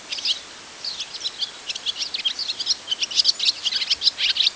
Hirundo rustica - Swallow - Rondine
DATE/TIME: 14/april/2005 (8 a.m.) - IDENTIFICATION AND BEHAVIOUR: one bird is flying over a wheat field. - POSITION: Poderone near Magliano in Toscana, LAT.N 42 36'/LONG.E 11 17'- ALTITUDE: +130 m. - VOCALIZATION TYPE: full song (one song phrase delivered in flight). - SEX/AGE: unknown - MIC: (A)